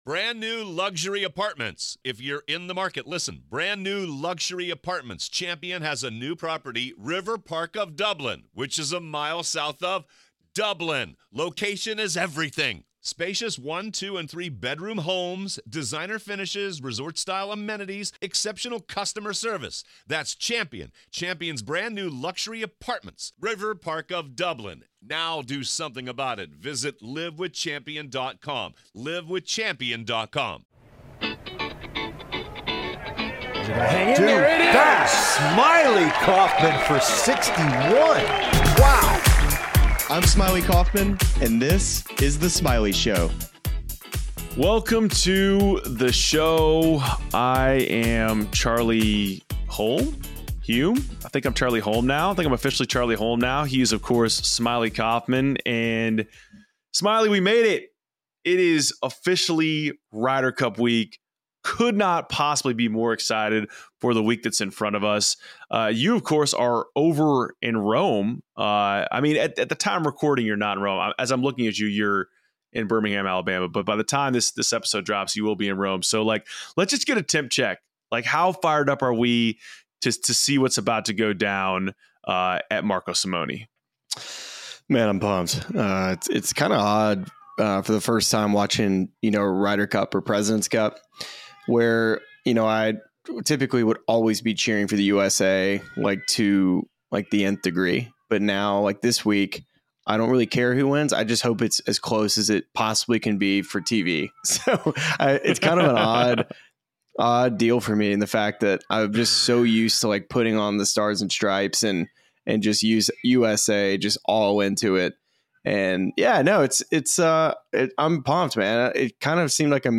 Smylie Kaufman is joined by European Tour winner, fellow "golden retriever," and host of the "Beef's Golf Club" podcast Andrew "Beef" Johnston for a two-part Ryder Cup collaboration. Smylie and Beef relive the late-night/early-morning FaceTimes they used to exchange before digging into a preview of the Team Europe roster ahead of the Ryder Cup. The two are then joined by Padraig Harrington - who made six Ryder Cup appearances and captained the European side in 2021 - to provide his perspective on the way that roster has transformed since the loss at Whistling Straits.